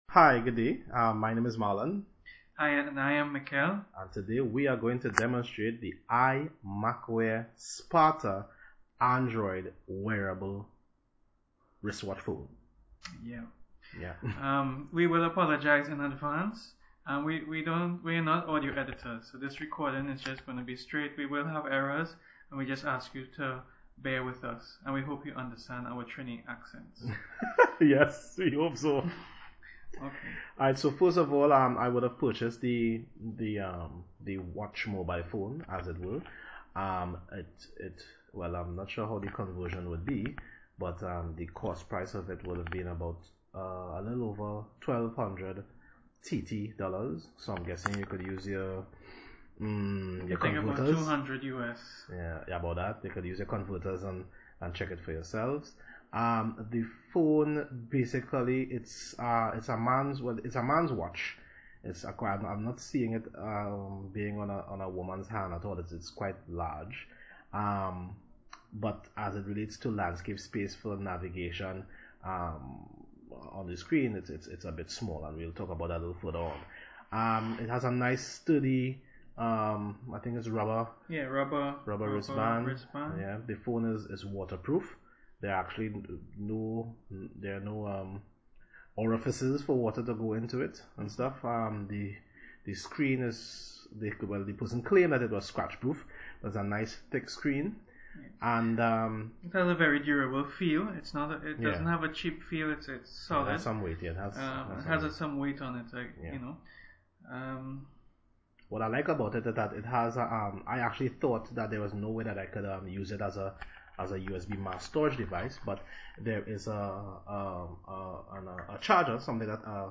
They also demonstrated answering a call on the device.